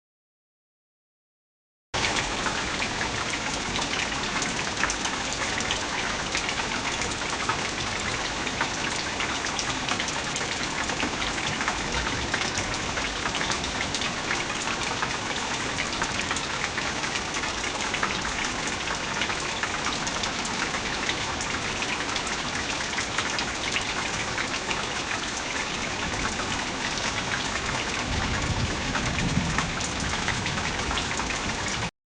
音频生成示例
• 雨声（The sound of rain.）
下雨的声音（The-sound-of-rain.）-1.mp3